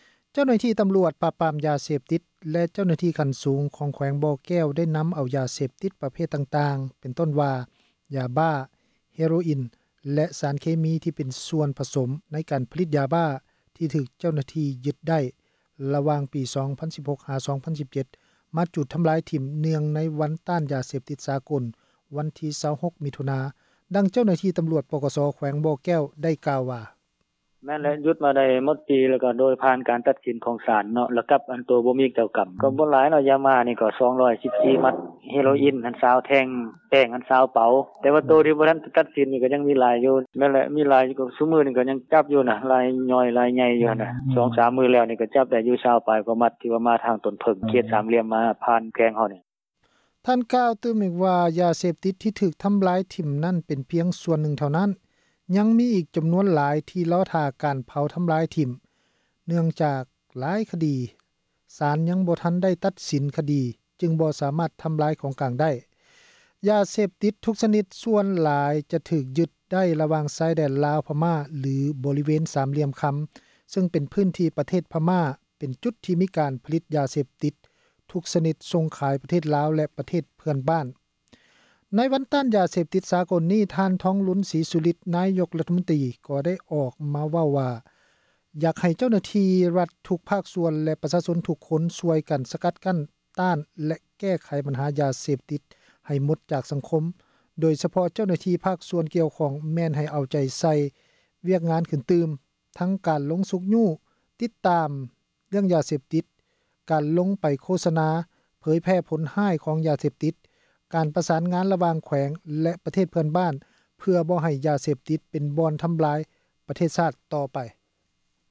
ດັ່ງເຈົ້າໜ້າທີ່ຕຳຣວດ ປກສ ແຂວງບໍ່ແກ້ວ ໄດ້ກ່າວວ່າ: